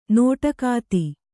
♪ nōṭakāti